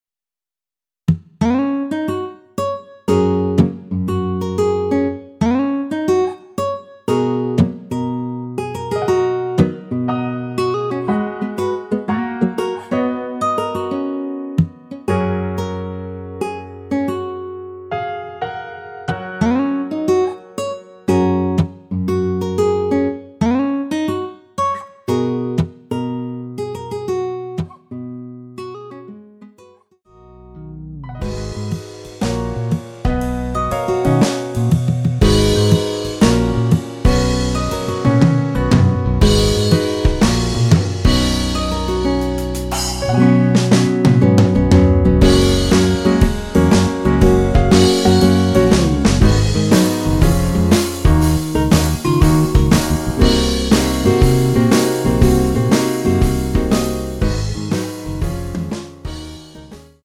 하이퀄리티 MR입니다.
F#
앞부분30초, 뒷부분30초씩 편집해서 올려 드리고 있습니다.
중간에 음이 끈어지고 다시 나오는 이유는